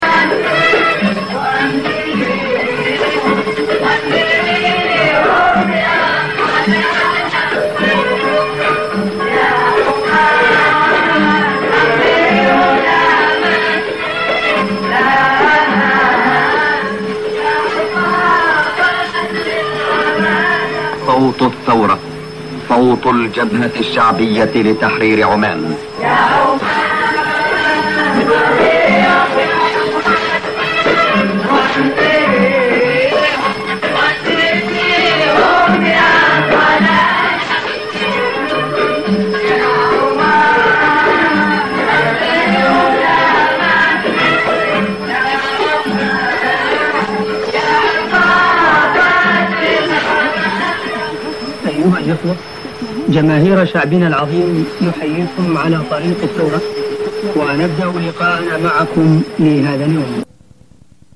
短波放送の録音をMP3でアップしました。
76年のニューステーマ曲、同エンディングと85年のニューステーマ曲音楽